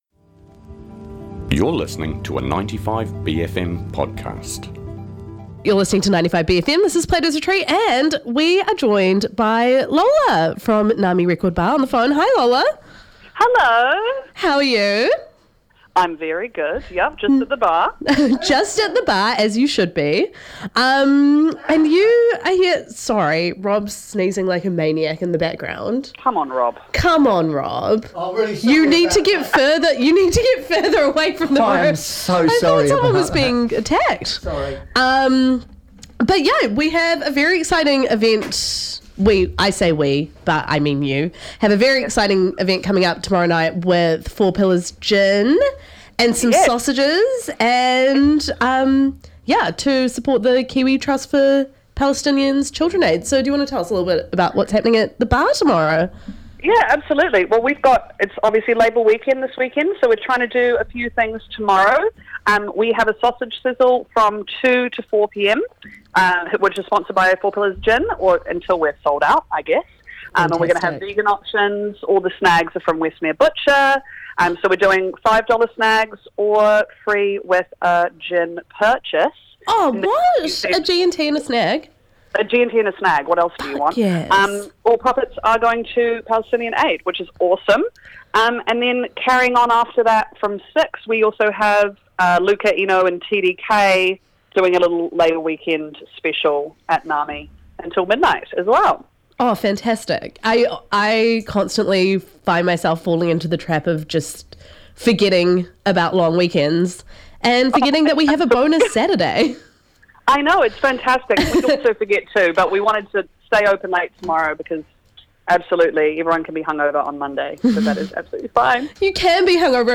$1 - $1000 records given equal treatment and airtime. For all that's good in Boogie, New Wave, Disco, House, Post-punk, Afro, Modern Soul, AOR, & much much more.